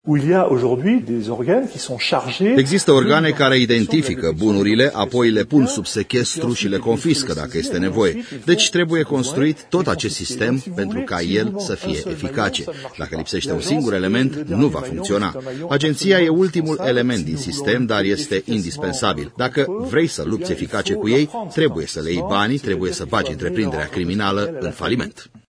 Într-un interviu pentru Digi24 şeful agentiei de la Paris, Charles Duchaine a explicat cum au reuşit francezii să crească substanţial gradul de recuperare a banilor de la infractori: